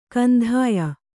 ♪ kandhāya